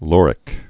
(lôrĭk, lŏr-)